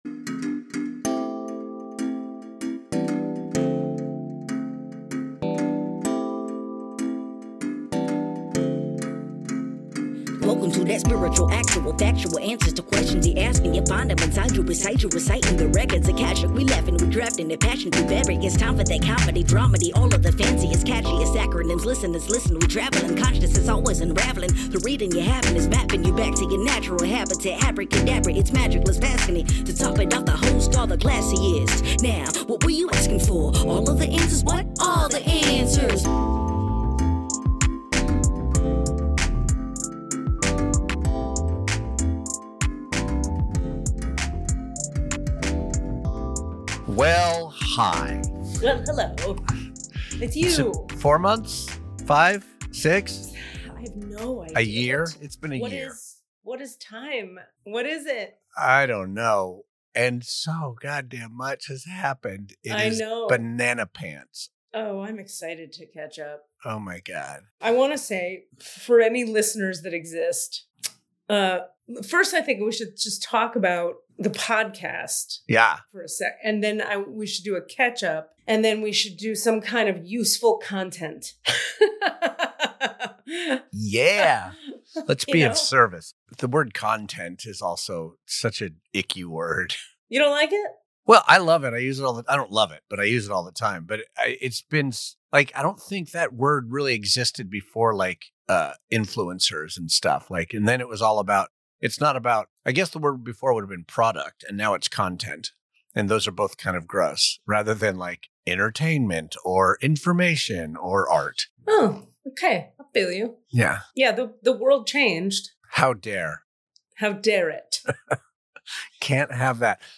The conversation takes a deep dive into the concept of human design, exploring how understanding one's unique design can offer clarity and direction in navigating life's complexities.